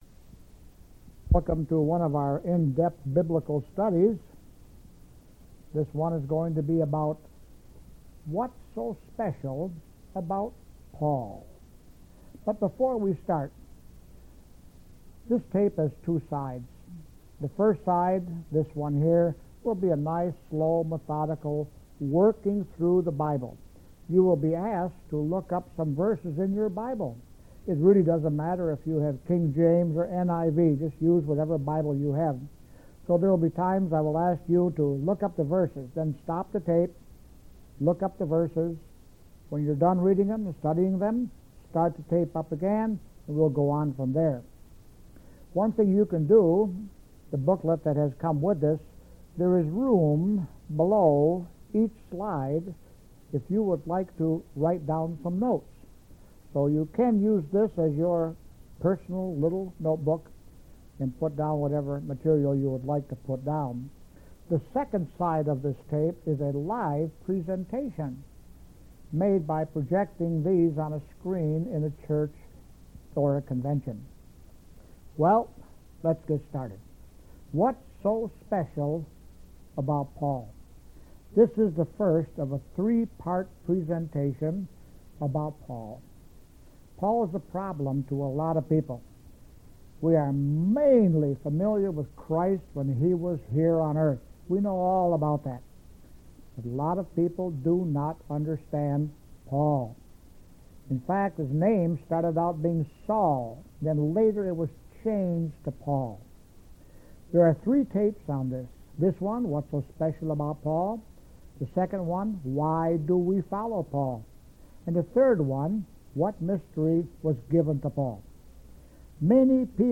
Related Files: Bible Study; Presentation